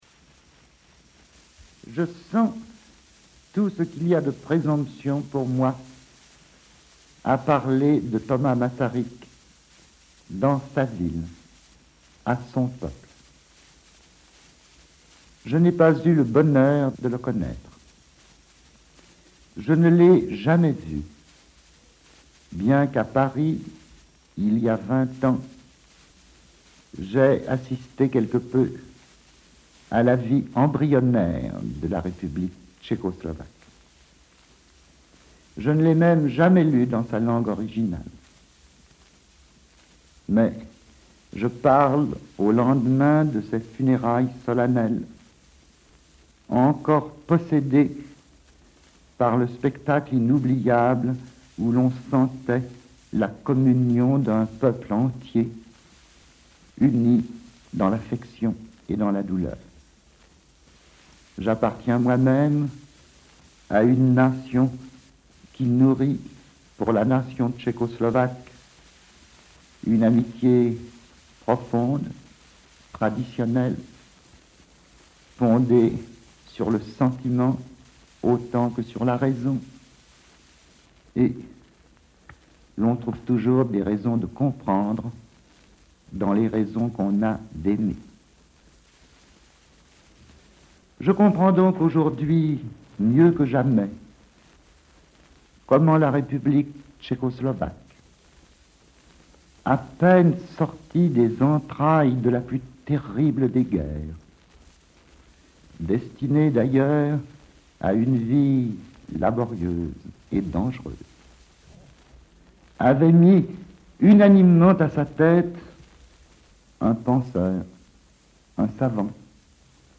Dans les archives de la Radio tchèque, nous avons retrouvé un hommage de Léon Blum au président tchécoslovaque défunt Tomáš G. Masaryk et d'autres sons d'époque.